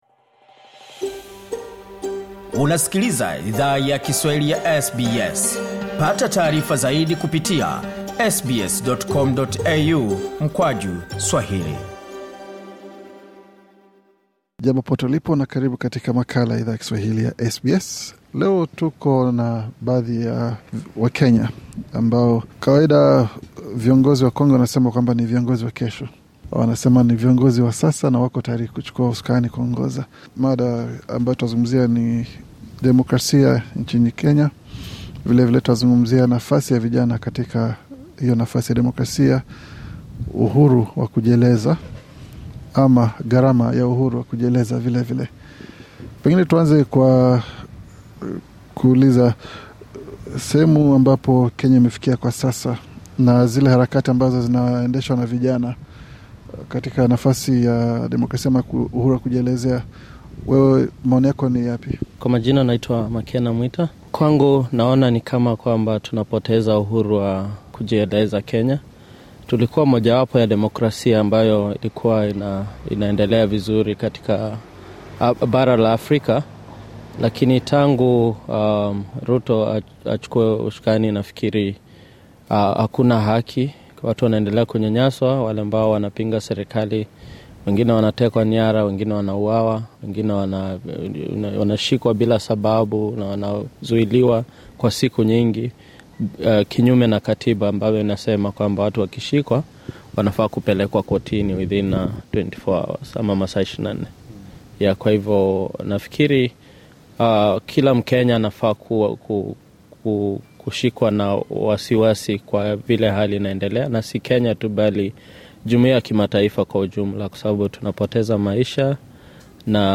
Katika mahojiano maalum, SBS Swahili ilizungumza na baadhi ya wakenya wanao ishi Australia walio funguka kuhusu visa hivyo.